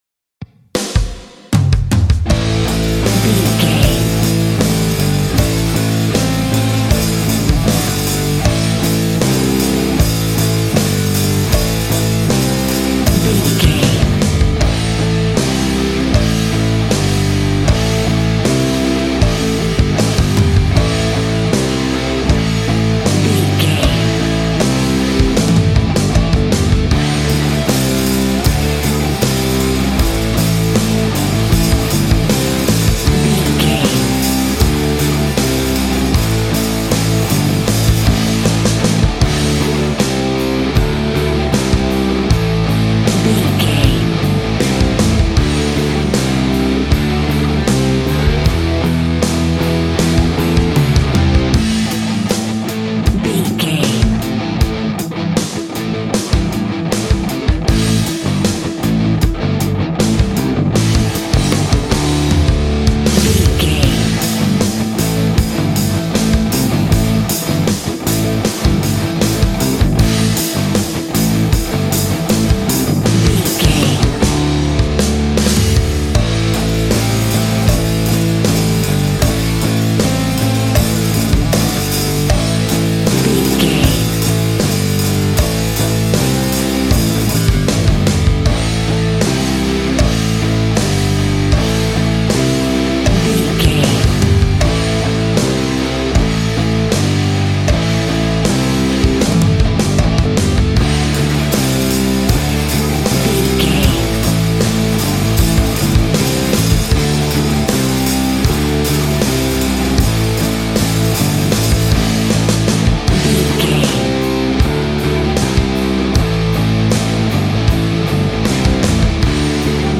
Aeolian/Minor
groovy
powerful
electric guitar
bass guitar
drums
organ